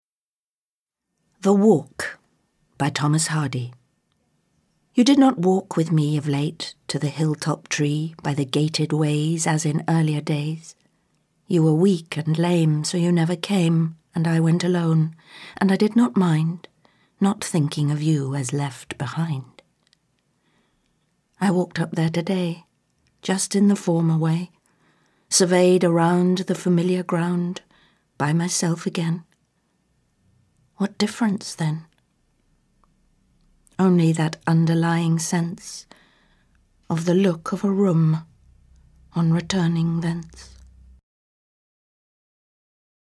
Harriet Walter reads "The Walk" by Thomas Hardy
In celebration of National Poetry Month, every day we're posting a new poem from the spoken-word album Poetic License, a three-CD set that features one hundred performers of stage and screen reading one hundred poems selected by the actors themselves.
Harriet Walter is a British actress who has performed with the Royal Shakespeare Company in productions of A Midsummer Night's Dream, Nicholas Nickleby, and Three Sisters, among other plays.